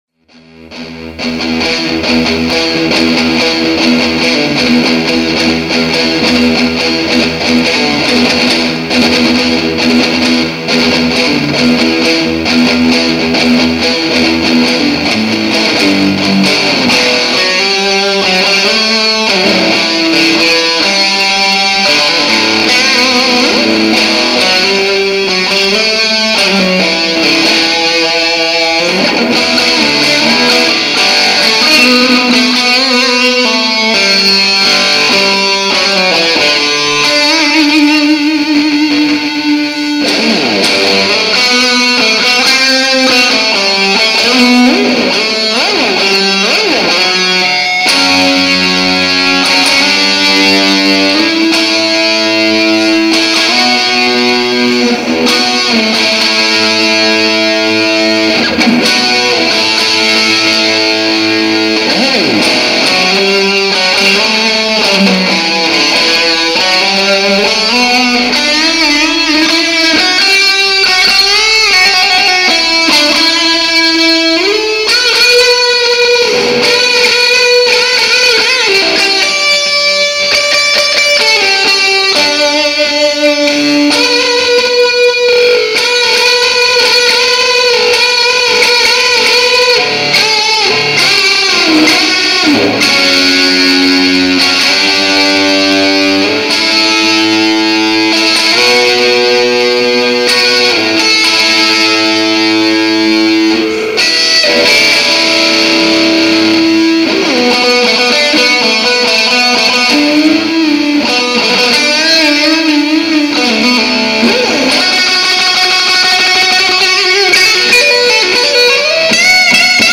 Вниз  Играем на гитаре